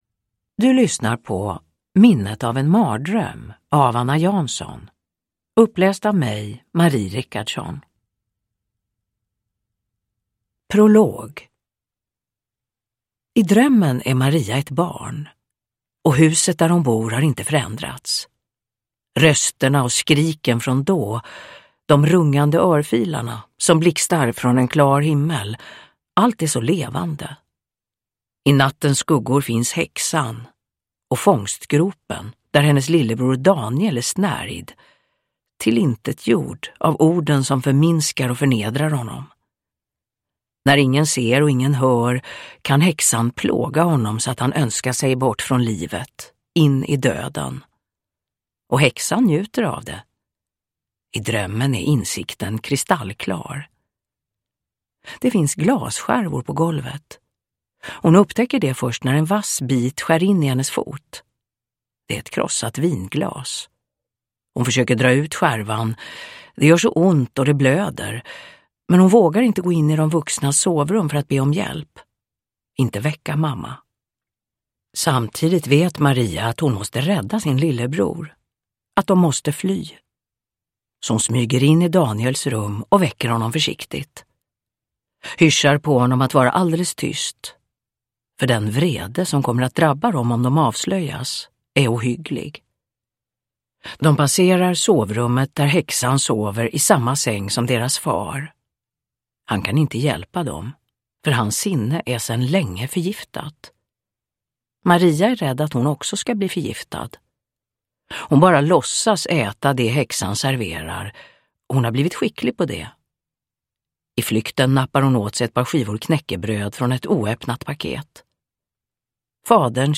Minnet av en mardröm – Ljudbok
Uppläsare: Marie Richardson